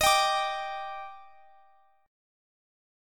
Listen to D#M7 strummed